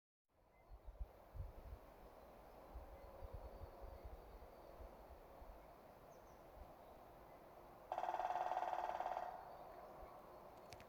Grey-headed Woodpecker, Picus canus
Ziņotāja saglabāts vietas nosaukumsmežā
StatusSinging male in breeding season
NotesĻoti aktīvi saucieni, bungošana.